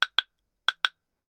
Samsung Galaxy Bildirim Sesleri - Dijital Eşik